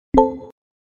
soundbox_tts_res
wakeup sound voice tts